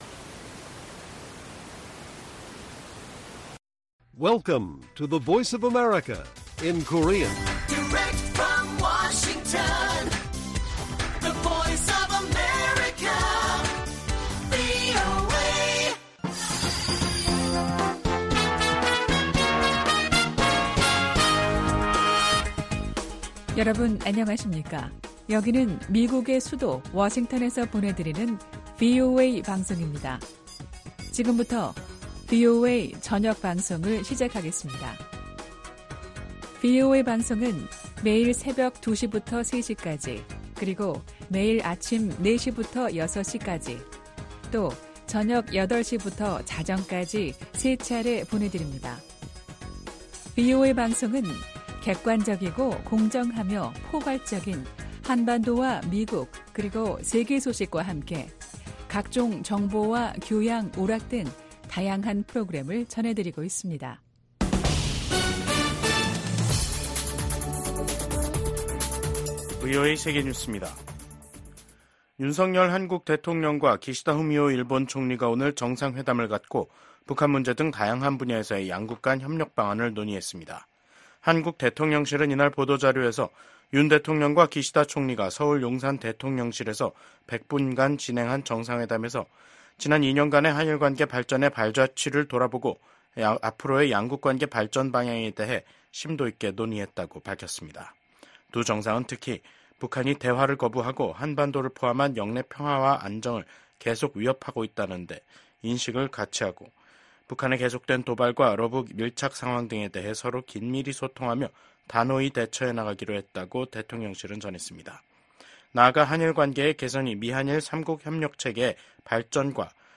VOA 한국어 간판 뉴스 프로그램 '뉴스 투데이', 2024년 9월 6일 1부 방송입니다. 윤석열 한국 대통령은 퇴임을 앞두고 방한한 기시다 후미오 일본 총리와 정상회담을 가졌습니다. 이에 대해 미국 국무부는 미한일 3국 관계가 공동 안보와 이익에 매우 중요하다고 강조했습니다. 미한 양국이 북한 핵 위협에 대비한 시나리오를 고위급 회담인 확장억제전략협의체 회의에서 논의한 것은 중대한 진전이라는 전문가들의 평가가 나왔습니다.